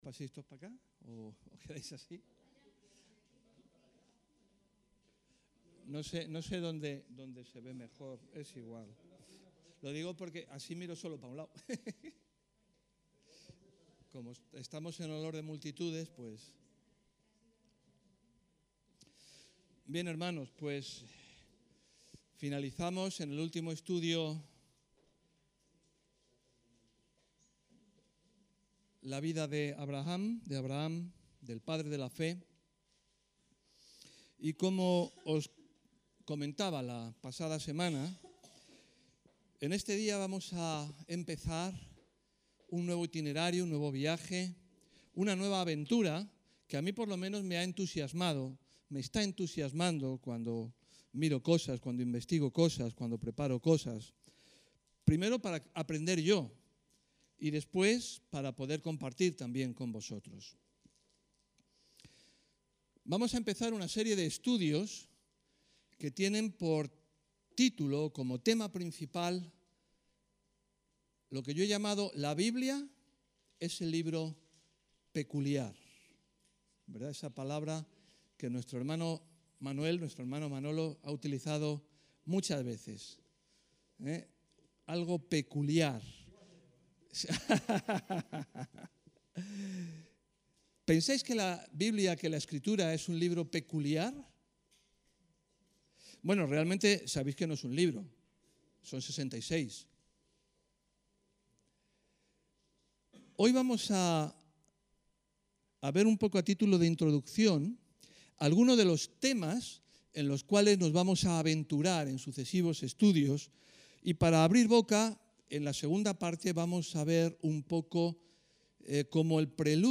El texto de la predicación se puede descargar aquí => biblia-01-introduccion